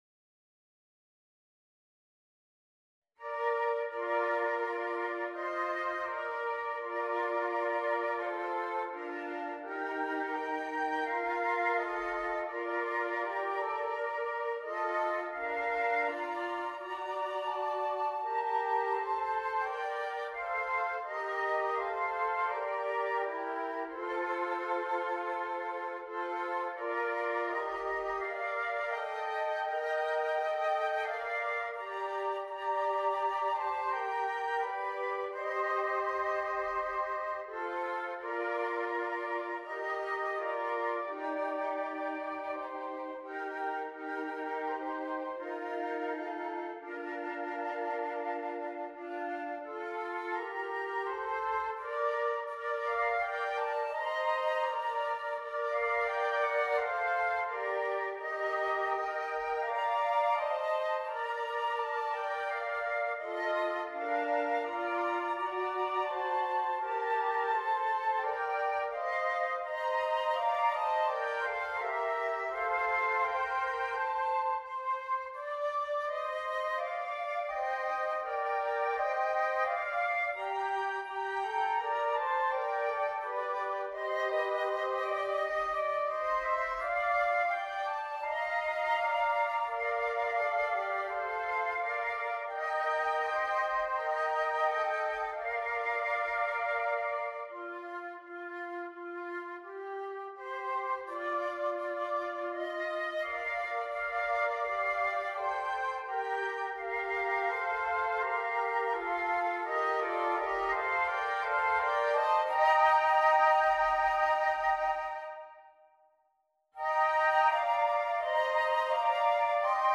Popular English hymn tune